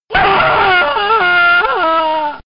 fire2.ogg